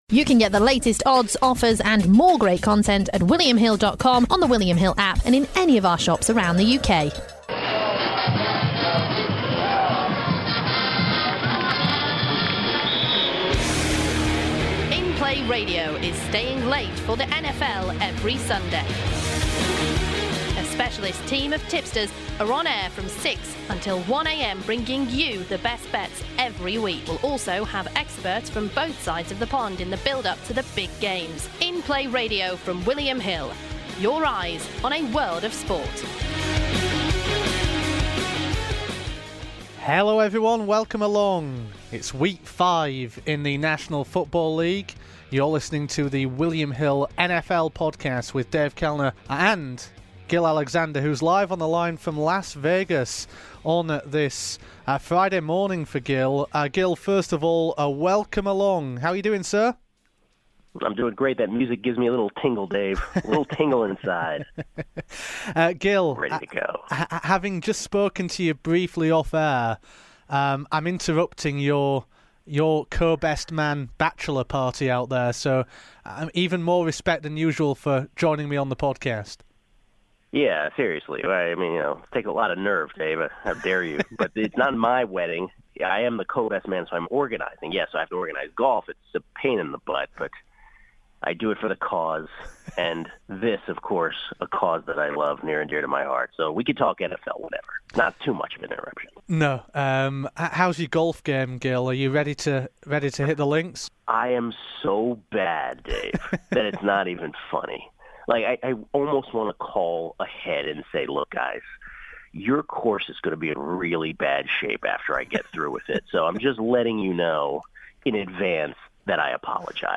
live from Las Vegas, to preview this weekend's televised matches in the National Football League.